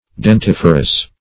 Search Result for " dentiferous" : The Collaborative International Dictionary of English v.0.48: Dentiferous \Den*tif"er*ous\, a. [L. dens, dentis, tooth + -ferous.] Bearing teeth; dentigerous.